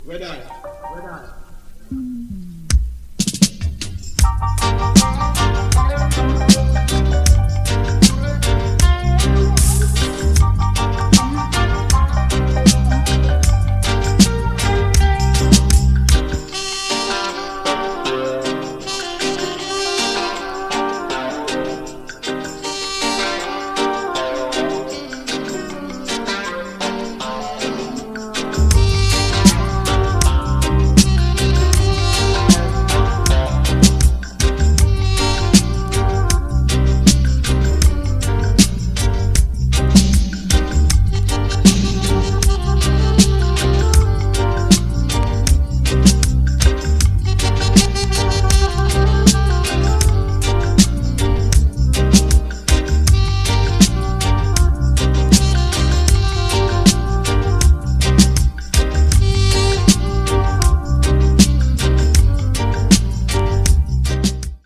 at Channel One studio in Kingston, Jamaica